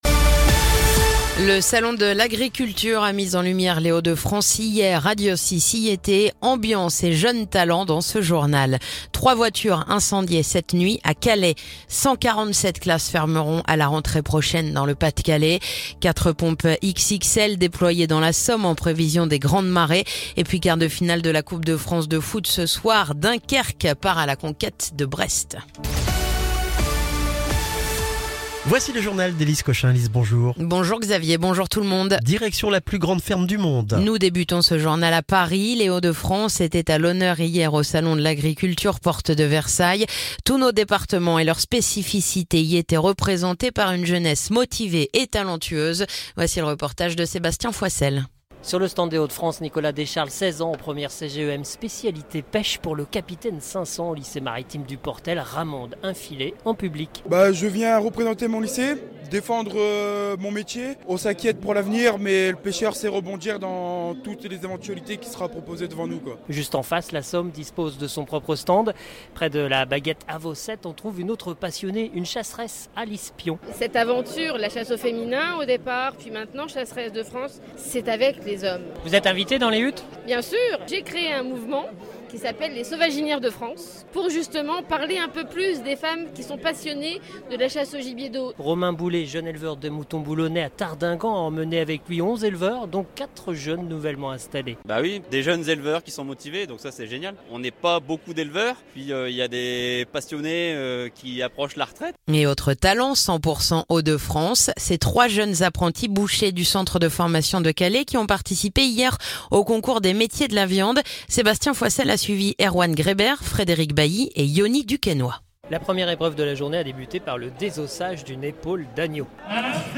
Le journal du mercredi 26 février